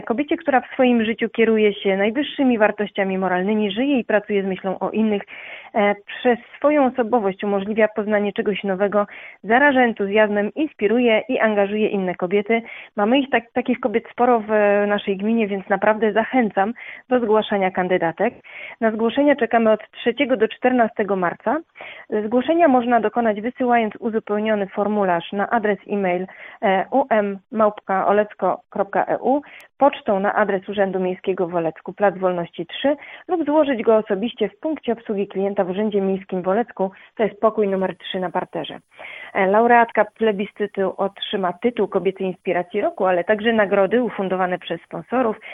Do udziału w plebiscycie zaprasza Sylwia Wieloch, zastępca burmistrza Olecka.